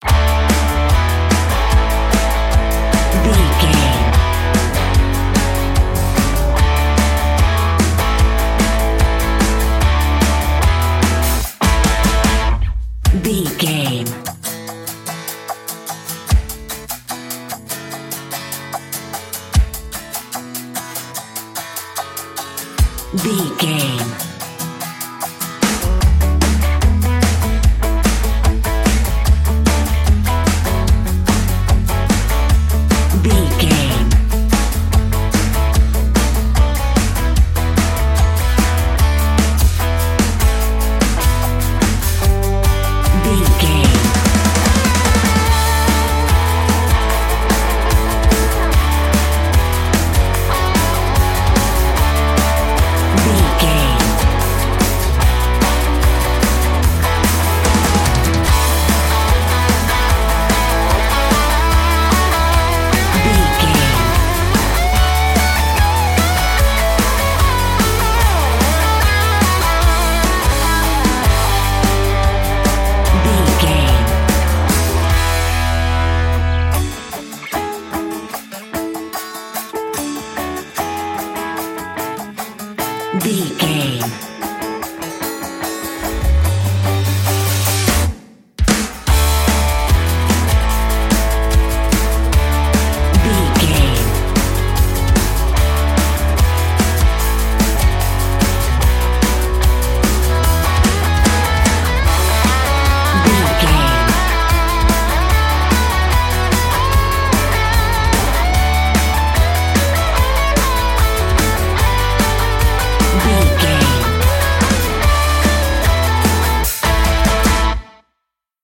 Epic / Action
Ionian/Major
Fast
drums
electric guitar
bass guitar